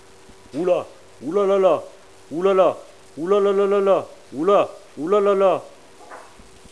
Quand il voit le sol se rapprocher, il lance des cris frénétiques qui donne à peu près ça :